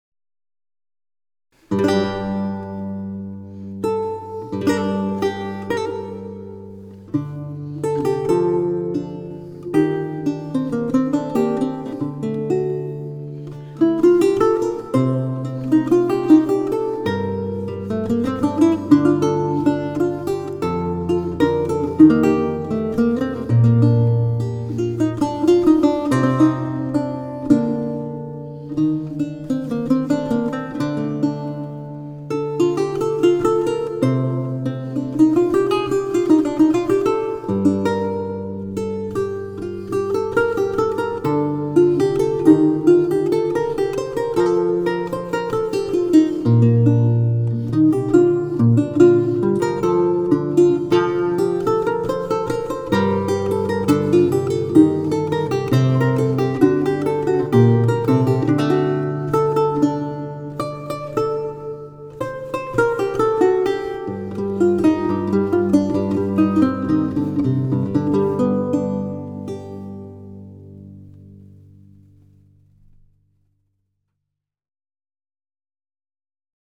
Preludio de John Dowland (Laúd renacentista)